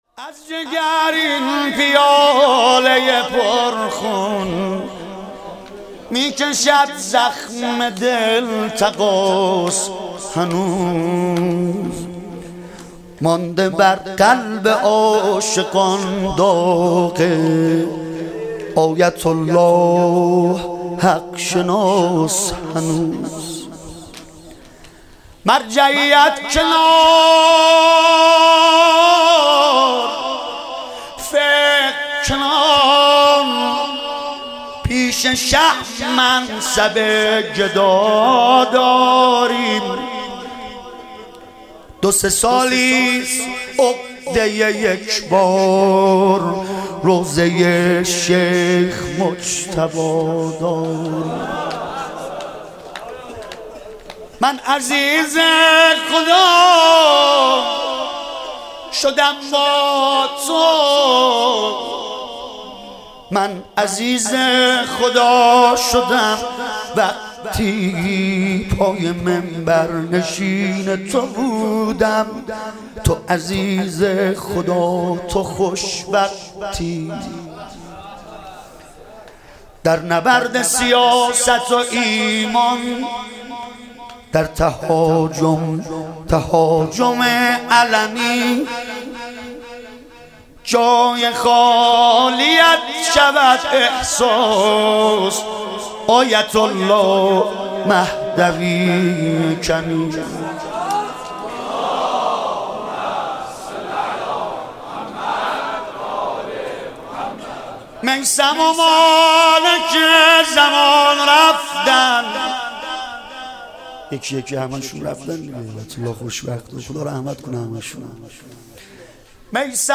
مداحی شنیدنی در وصف اساتید اخلاق
در هیئت هفتگی میثاق با شهدا